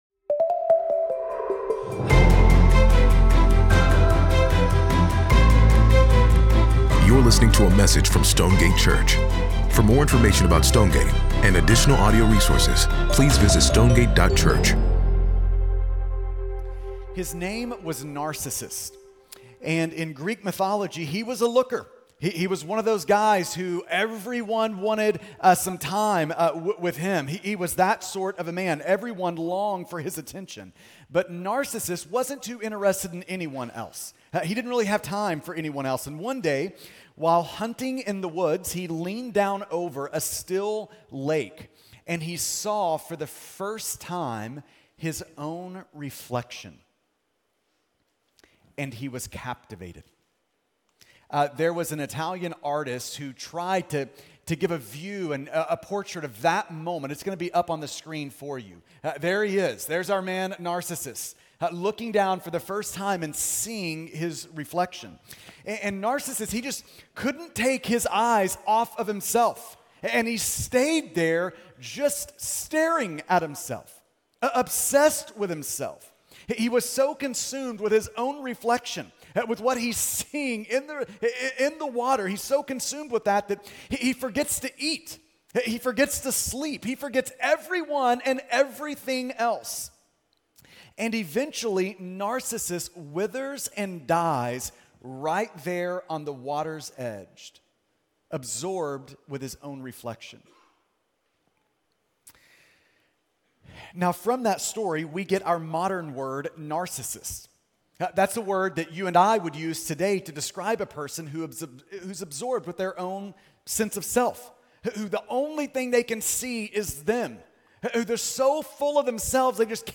Sermon pod 12.21.mp3